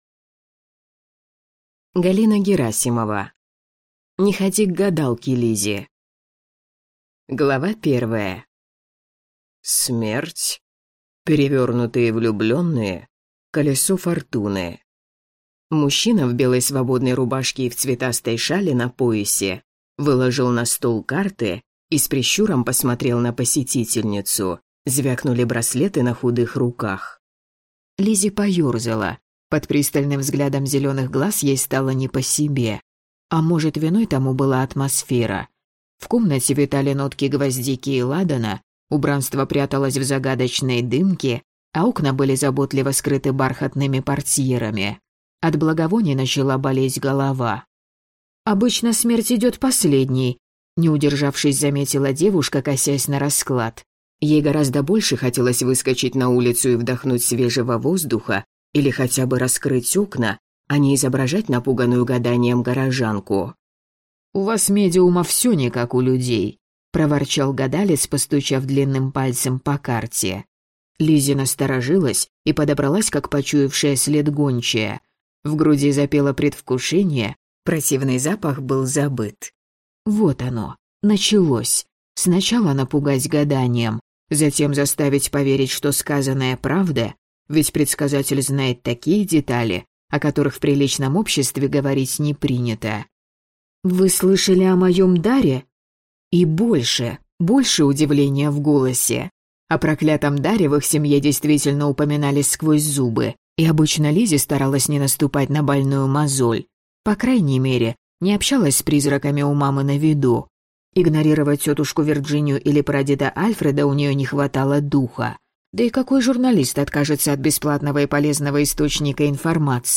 Аудиокнига Не ходи к гадалке, Лиззи!